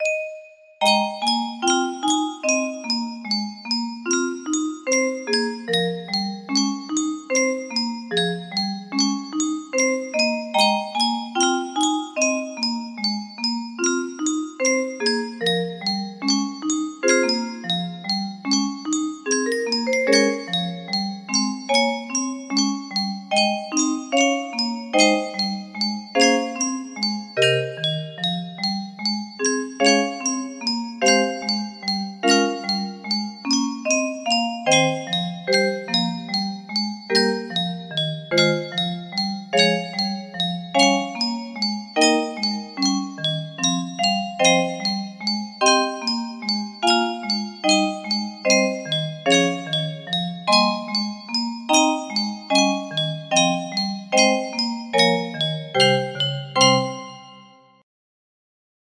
Lullaby music box melody
Full range 60
A piece written for my first-born. Composed in 6/8.